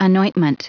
Prononciation du mot : anointment
anointment.wav